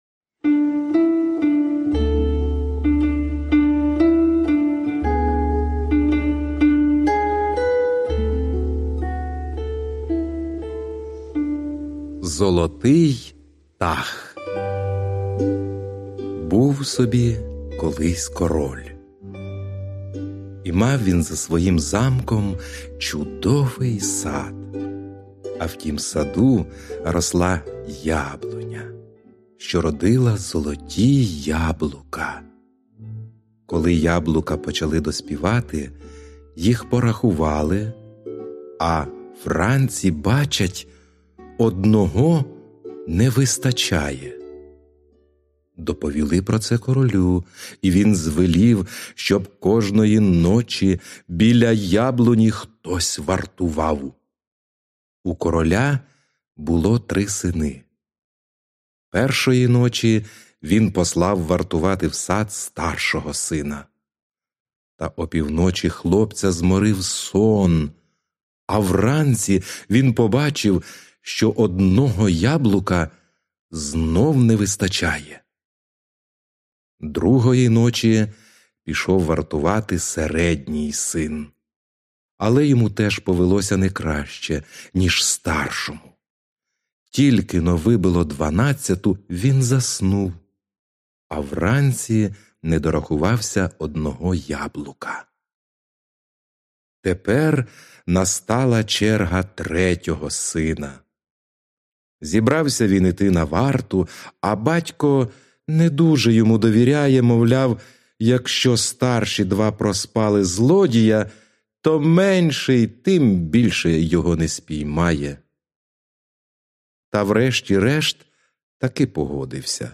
Аудіоказка Золотий птах